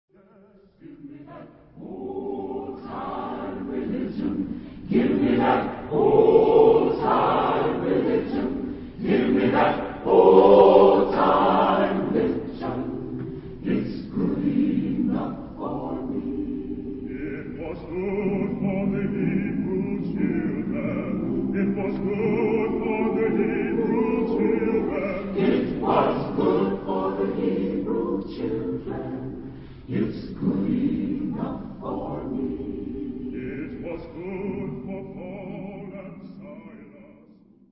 Genre-Style-Forme : Sacré ; Spiritual Afro-Américain
Caractère de la pièce : modéré ; heureux
Type de choeur : SATB  (4 voix mixtes )
Tonalité : sol majeur
Sources musicologiques : Soprano solo is optional obligato.